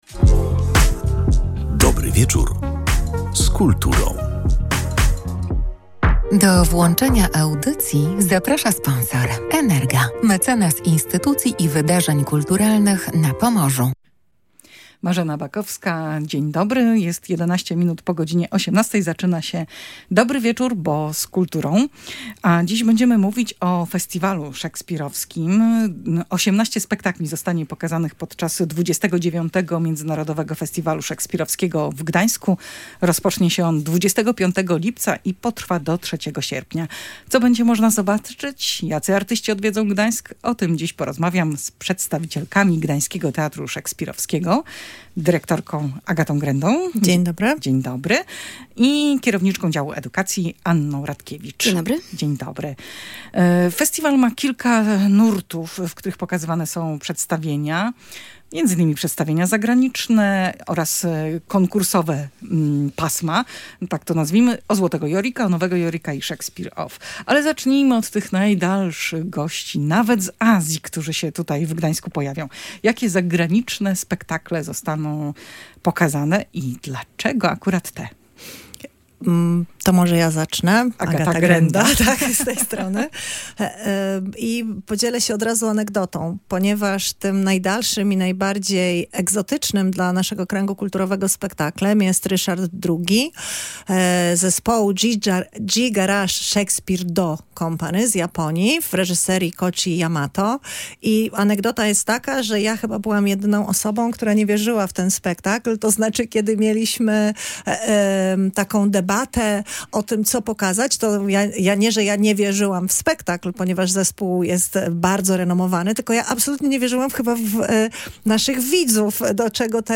W audycji na żywo o czekających atrakcjach opowiadały w rozmowie z